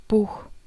Voiceless velar fricative (x)
German Buch
[buːx] 'book' See Standard German phonology